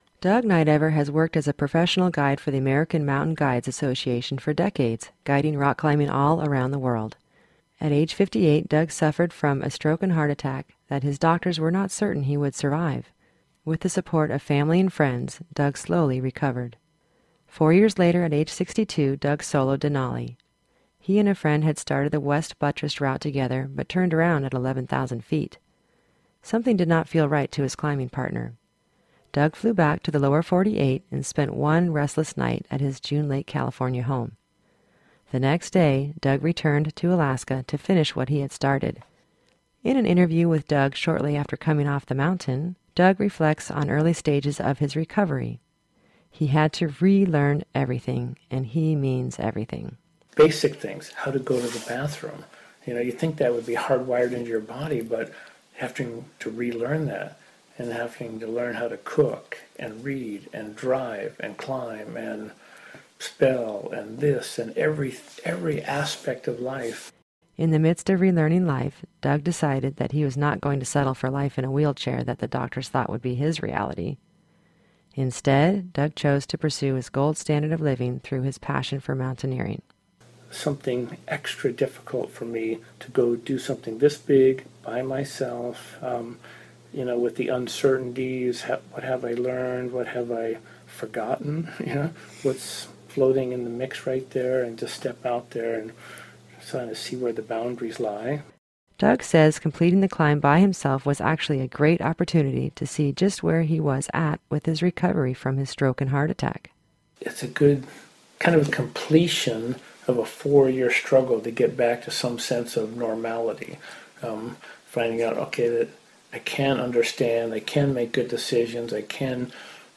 He had to relearn everything, and he means everything.